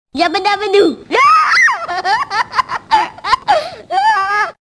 Yabba _Laugh
Jingle-25-Yabba_Laugh.mp3